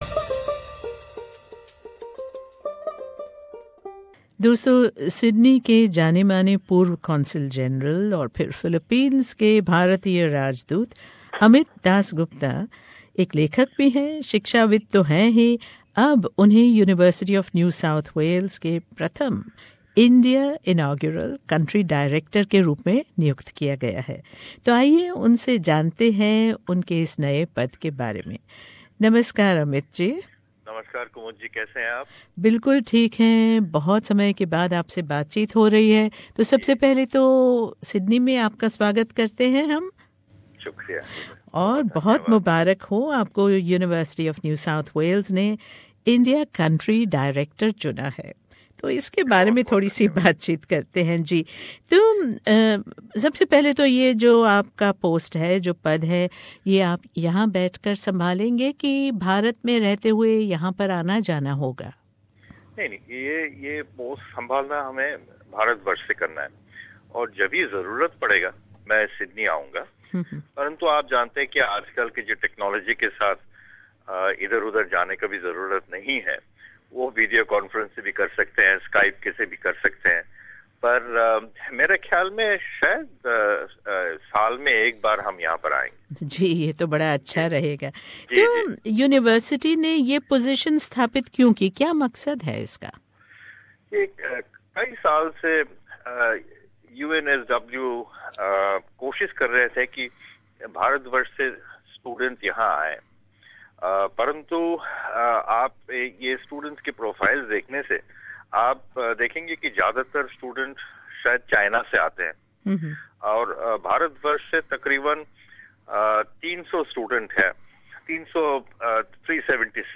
UNSW ने पूर्व राजदूत शिक्षाविद और लेखक श्री अमित दासगुप्ता को अपना प्रथम इंडिया कंट्री डायरेक्टर नियुक्त किया है। प्रस्तुत है श्री दासगुप्ता के नज़रिए पर भेंट वार्ता।